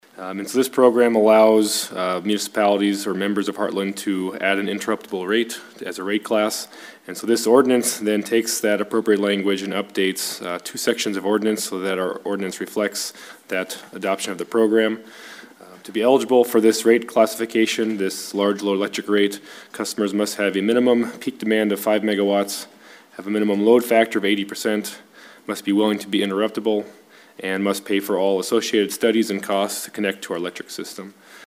City Administrator Jameson Berreth gave an overview of the ordinance.